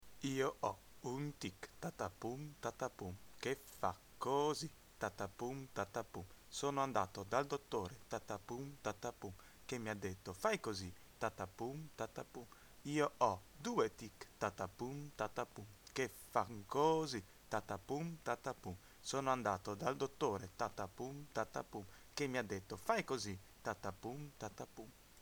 Il bansista conduce il canto, ma il gruppo copier� contemporaneamente i suoi gesti.